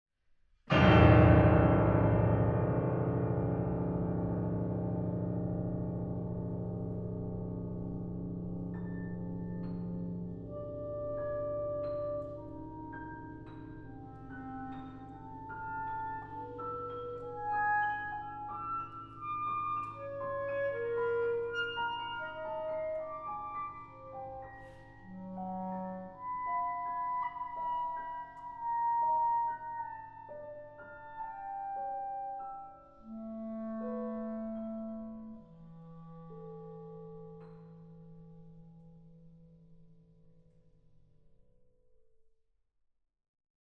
Klarinette
Klavier
Sopran
Aufnahme: Tonstudio Ölbergkirche, Berlin, 2023